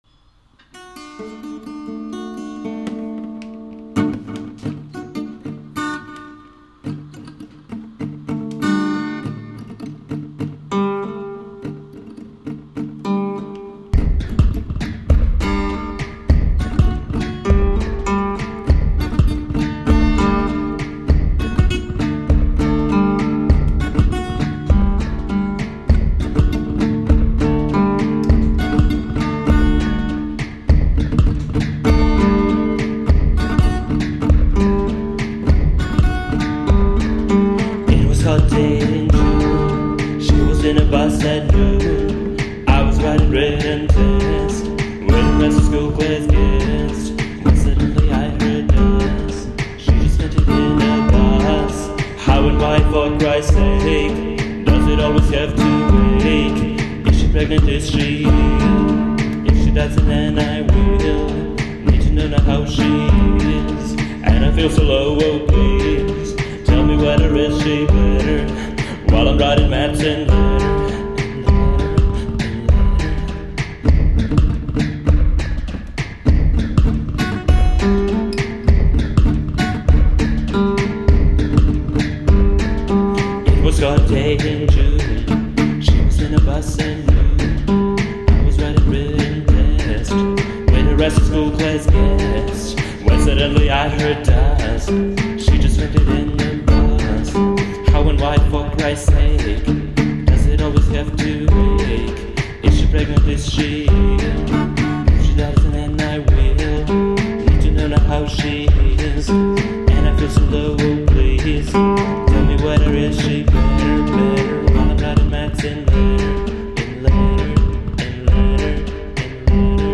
ALEATORIČKO INTUITIVNO-EKSPERIMENTALNi